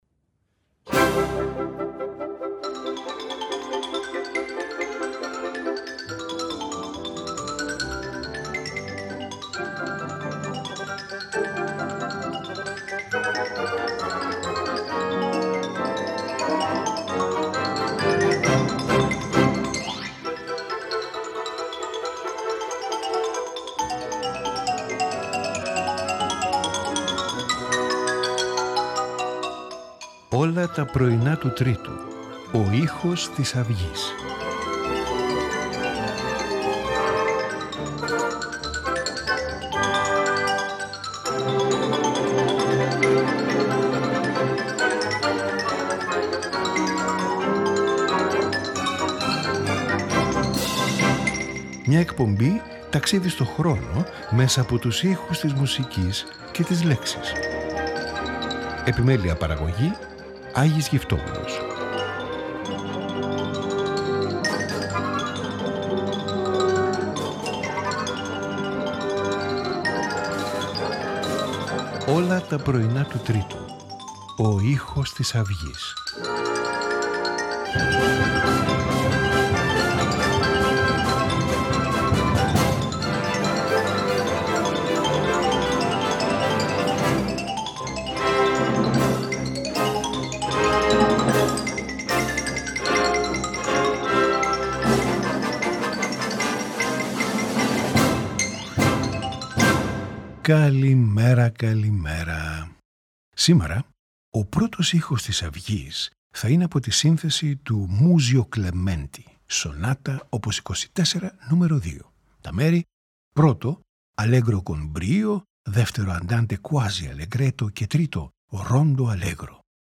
for violin and piano
for violin, cello and orchestra